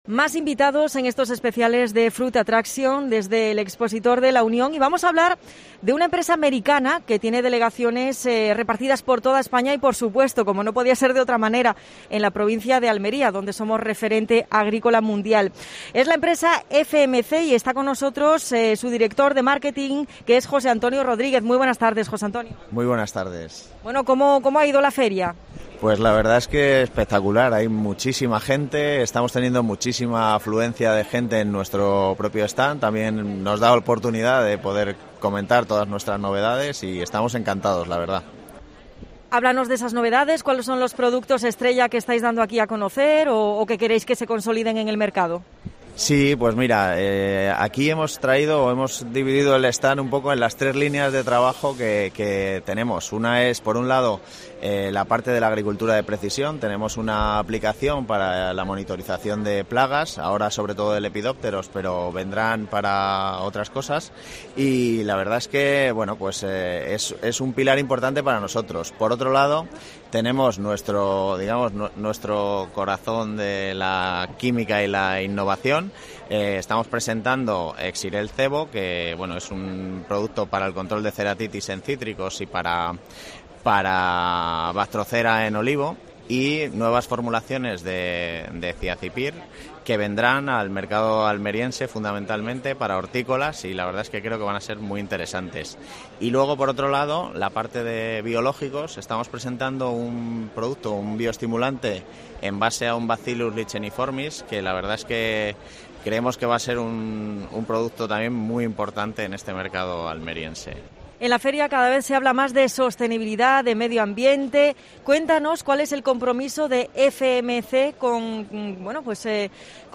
Entrevista
en Fruit Attraction.